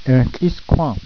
Eh ree ttl-ees koo-ehn